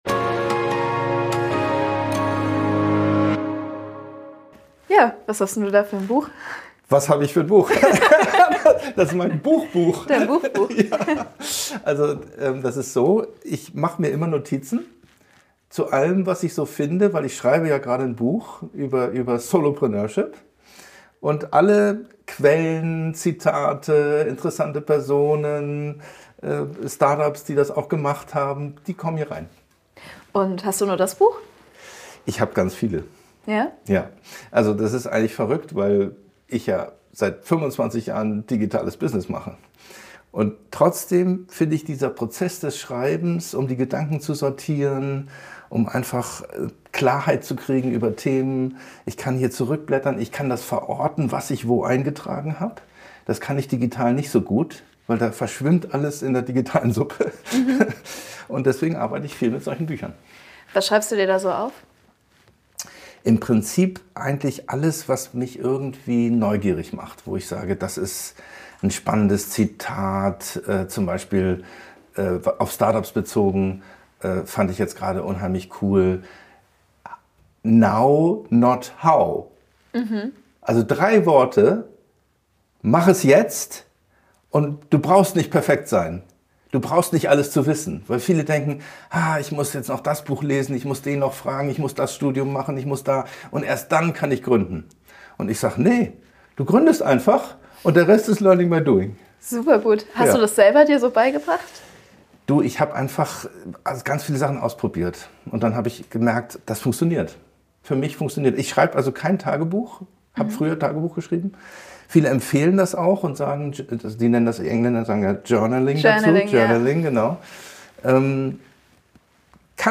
Zusammenfassung Solopreneurship und Lernen durch Erfahrung Der Interviewte beschreibt seinen Weg als Solopreneur, der durch Learning by Doing und Ausprobieren geprägt ist.